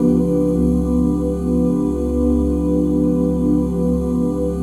OOHC#FLAT5.wav